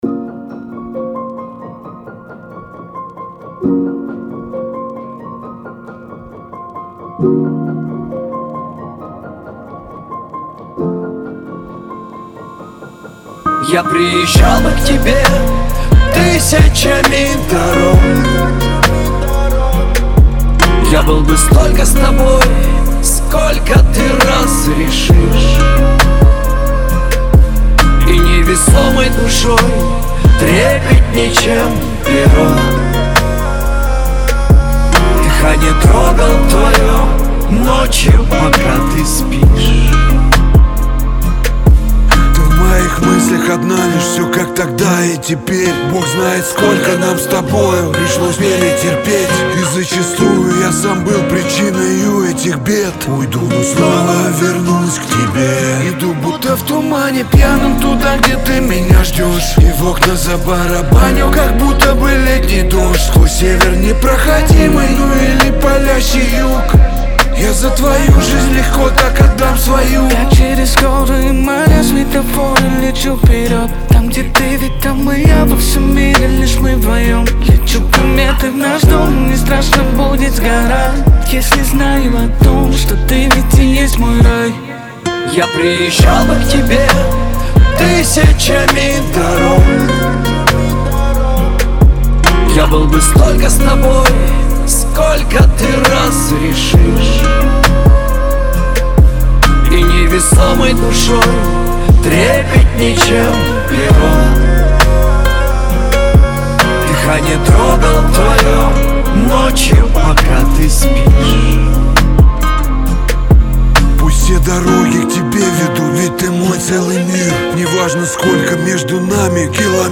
диско
Шансон
дуэт , эстрада